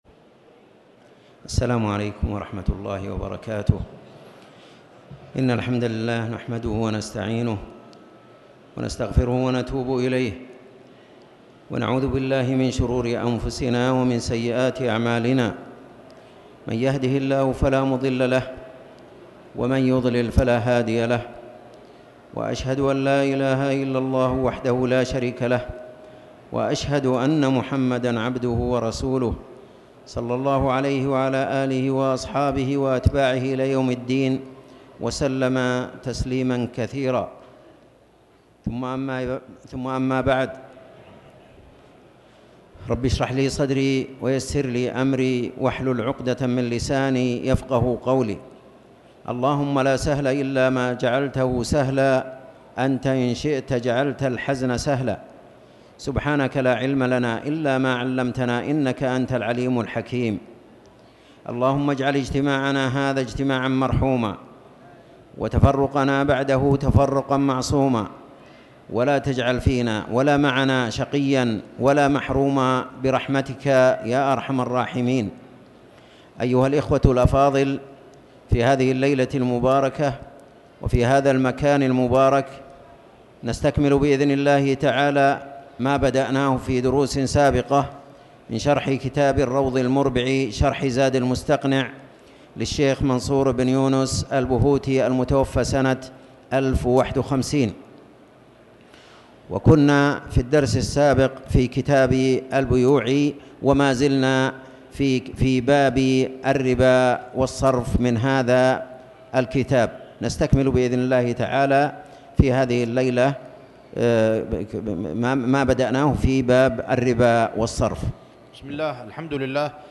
تاريخ النشر ٧ جمادى الآخرة ١٤٤٠ هـ المكان: المسجد الحرام الشيخ